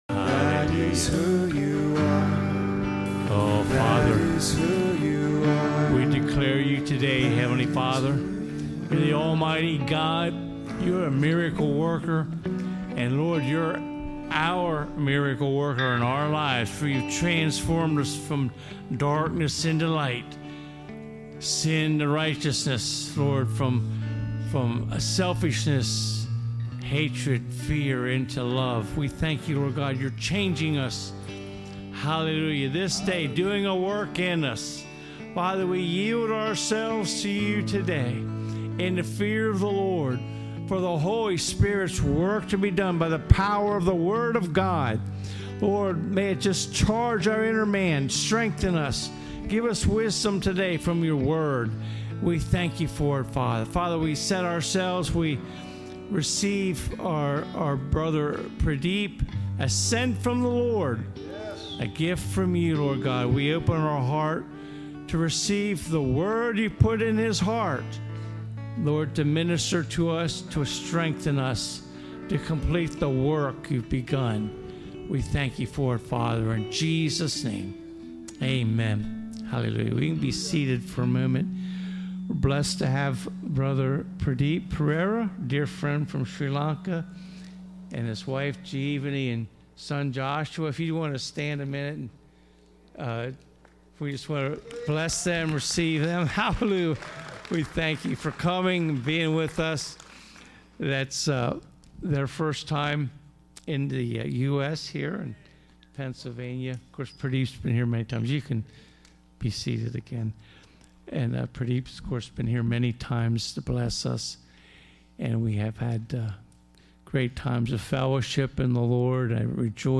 Preaching Service